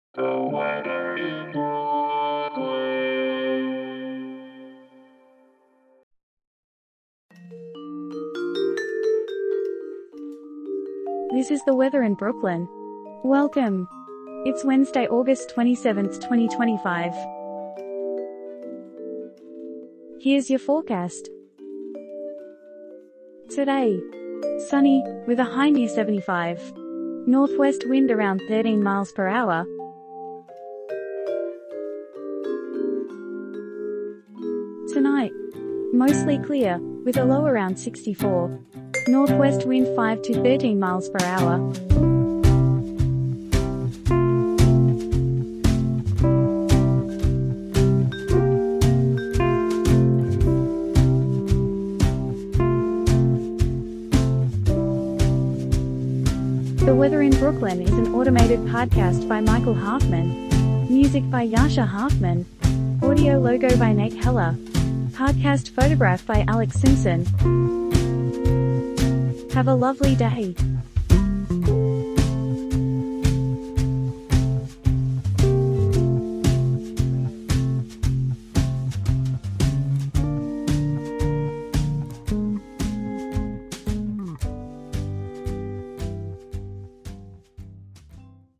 is generated automatically
Weather forecast courtesy of the National Weather Service.